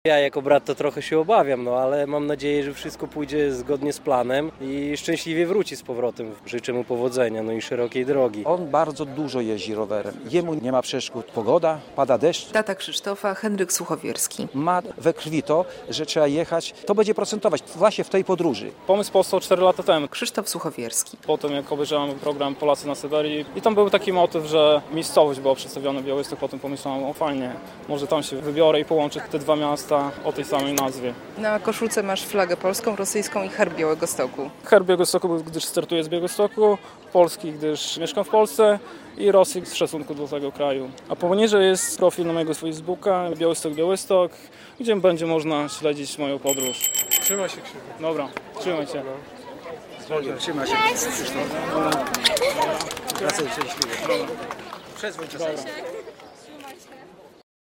Rowerowa wyprawa do Białegostoku na Syberii - relacja